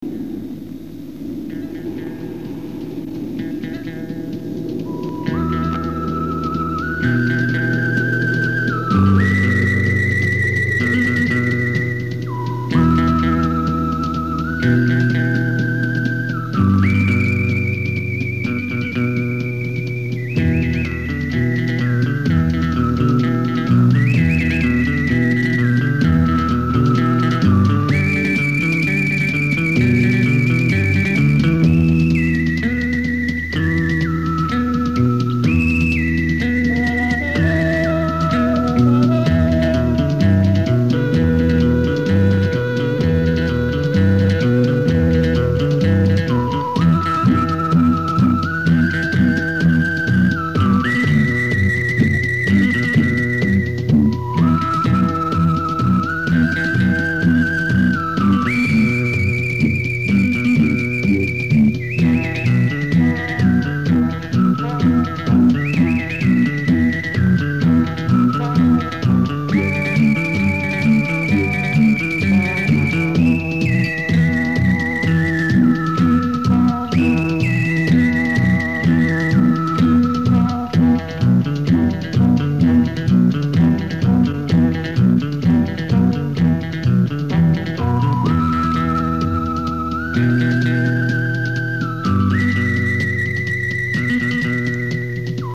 It had the best music I’ve ever heard in a ninja film.